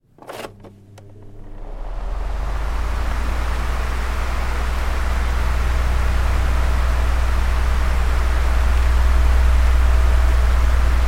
家用 " 风扇快速
描述：台式风扇速度快